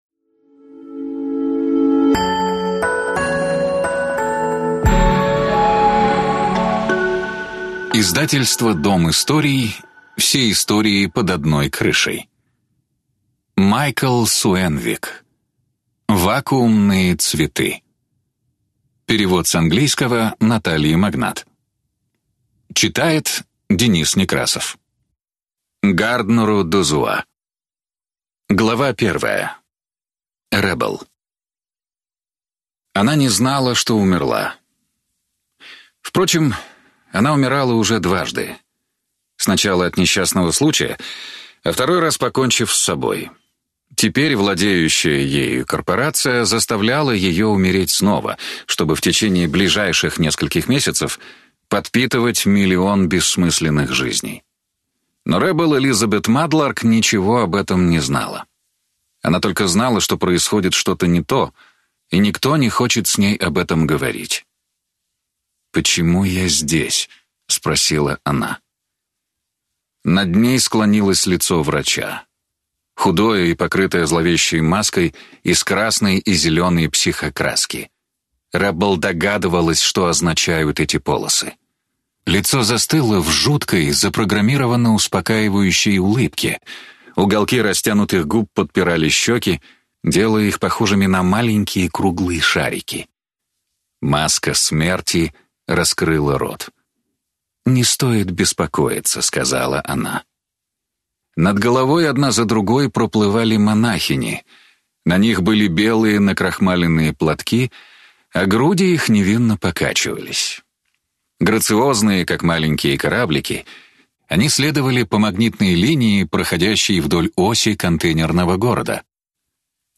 Аудиокнига Вакуумные цветы | Библиотека аудиокниг